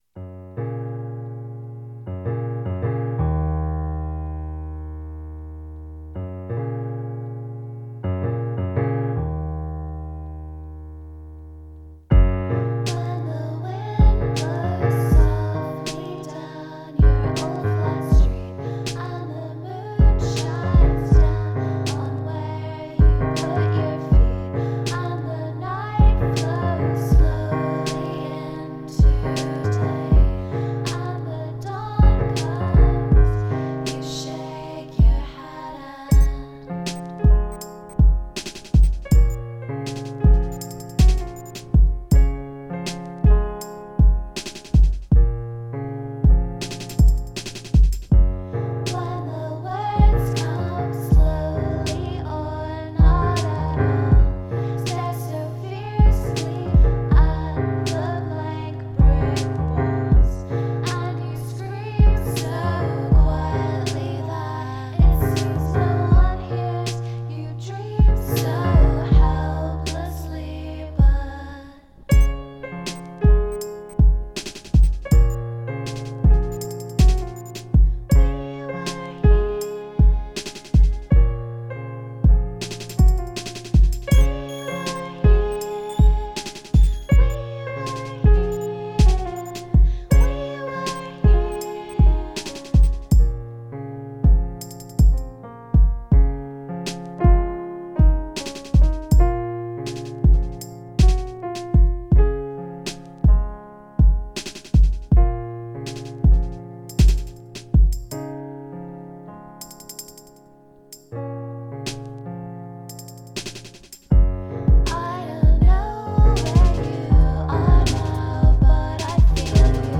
verse: g flat major, a flat major
chorus: d flat major, b flat minor
• vocal harmonies = oh my yes.